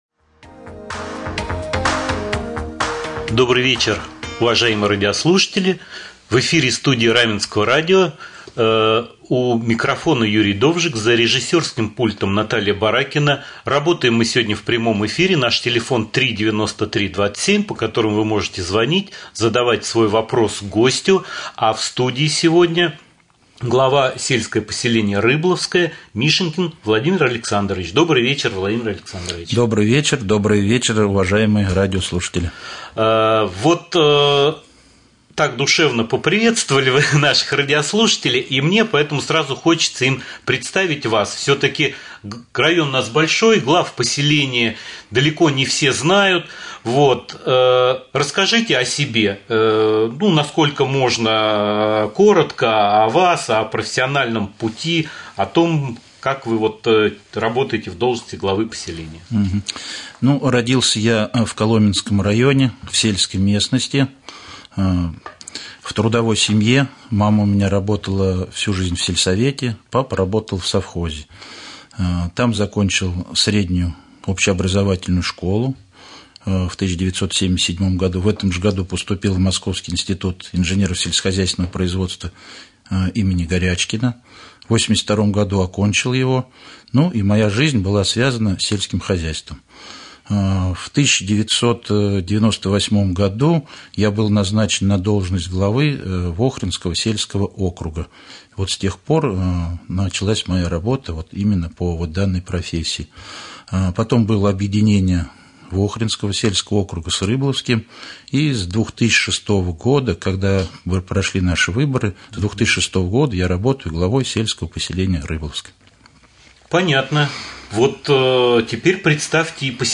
Прямой эфир с главой с.п.Рыболовское Владимиром Мишенькиным